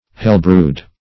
\Hell"brewed`\